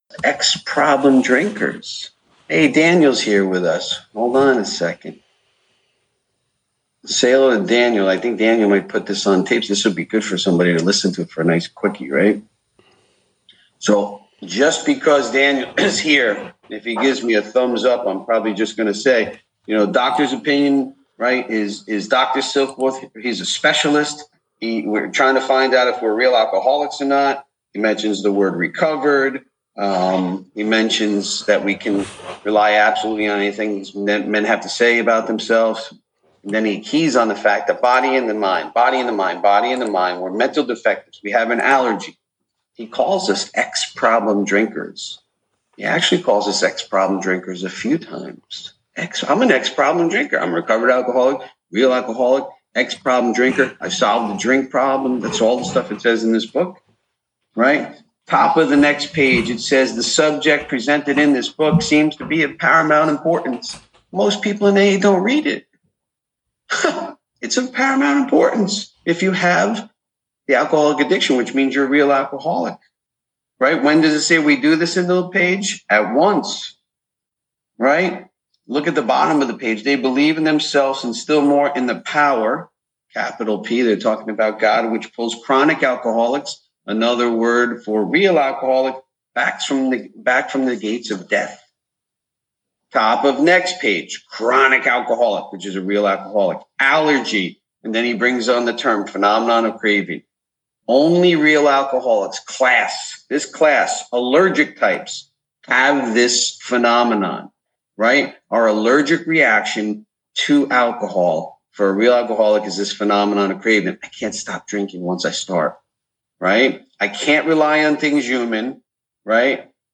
AA Speaker Recordings AA Step Series Recordings Book Study